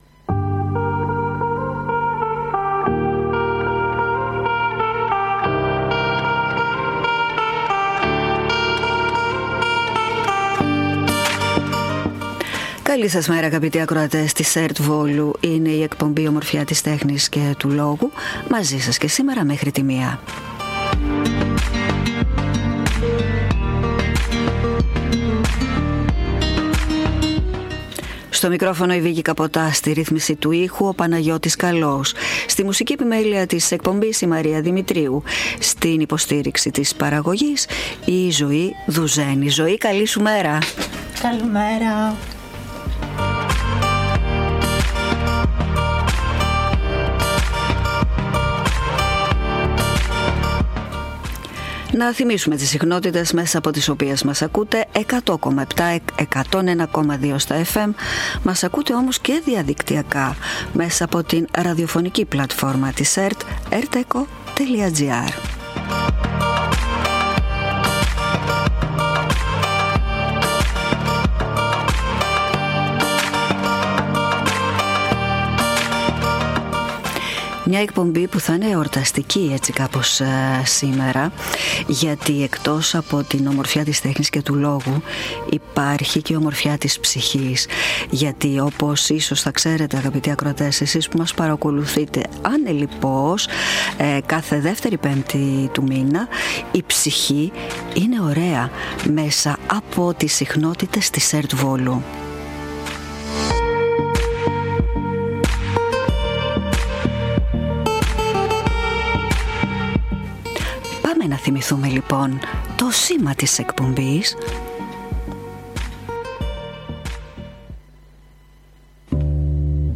«Η ψυχή είναι ωραία!»: Ραδιοφωνική εκπομπή του Δικτύου Διασύνδεσης των δομών ψυχικής υγείας Μαγνησίας.
Από την εκπομπή παρουσιάζονται θέματα που αφορούν στην ψυχική υγεία, ενηλίκων και παιδιών και σε θέματα που αφορούν σε εξαρτήσεις. Παράλληλα, ακούγονται μικρά σχετικά αποσπάσματα από την λογοτεχνία και το θέατρο και προτείνονται βιβλία και ταινίες για τους αναγνώστες και κινηματογραφόφιλους ακροατές.